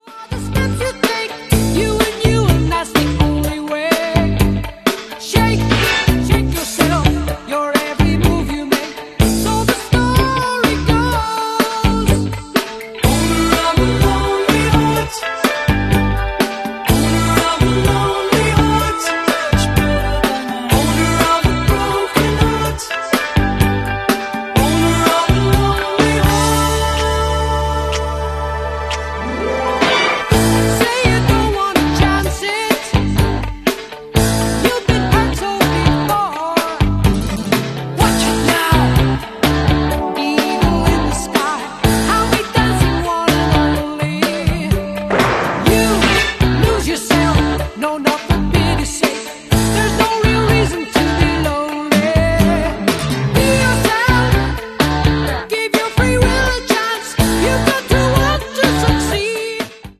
this track blends rock with cutting-edge digital sampling
bass guitar
piano